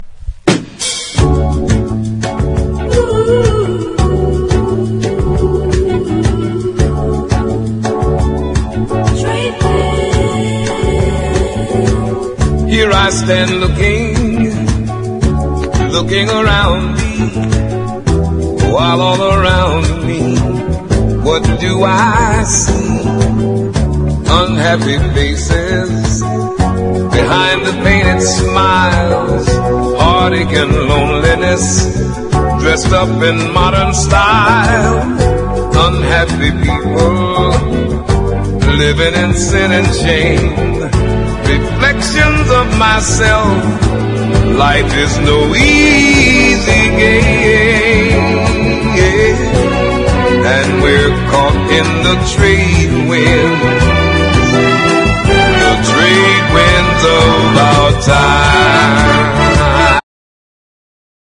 SOUL / SOUL / 70'S～ / DISCO / DRUM BREAK
海外からジャパニーズ・ファンクと評価される78年和製オーケストラ・ディスコ！